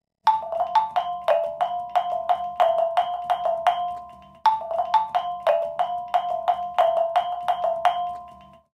Traditional Philippine Instrument
Kulintang
Audio file of the Kulintang
Sound-of-the-intrument.wav